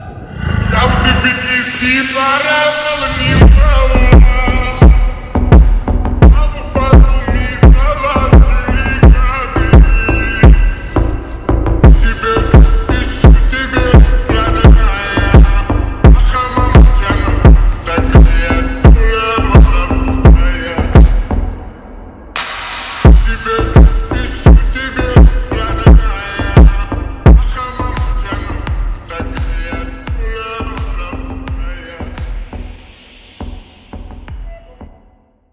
громкие
remix
восточные мотивы
мощные басы
Trap
G-House
блатные